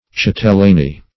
Chatellany \Chat"el*la*ny\, n.